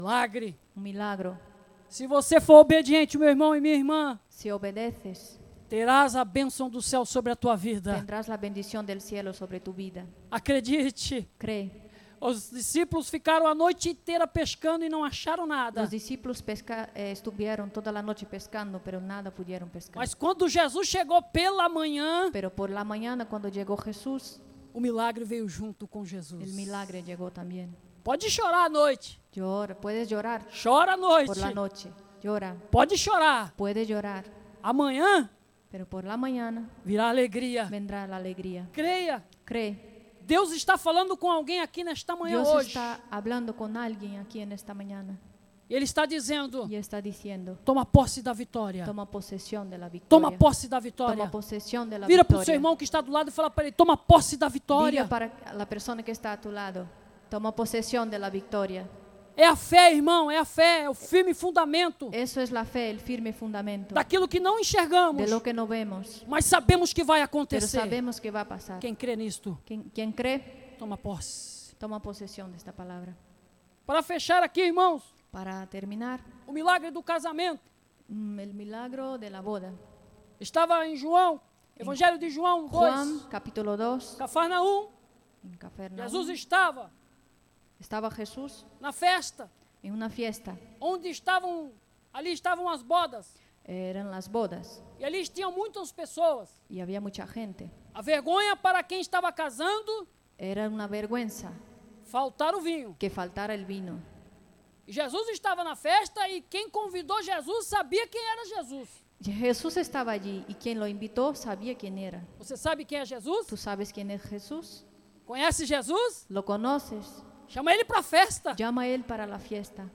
Predicaciones 2022